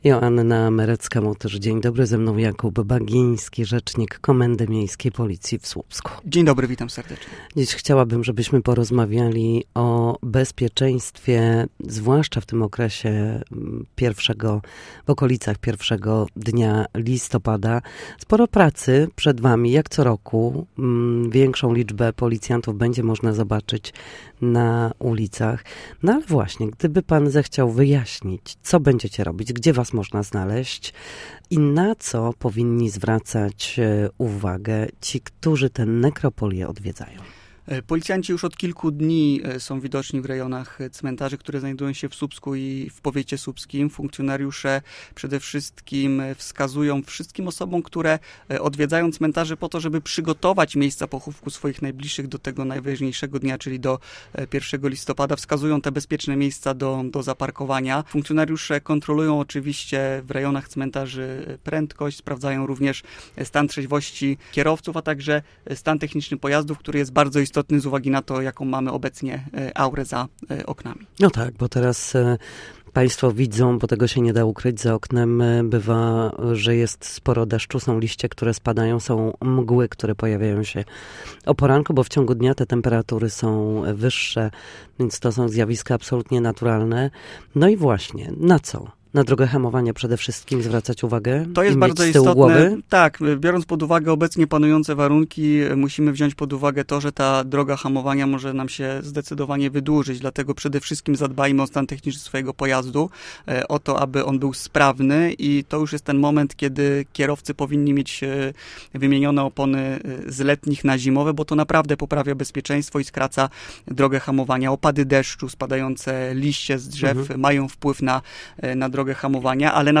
Na naszej antenie mówił o zachowaniu szczególnej ostrożności i bezpieczeństwie podczas wyjazdów związanych ze świętem Wszystkich Świętych.